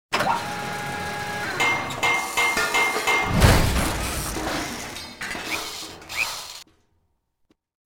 Repair7.wav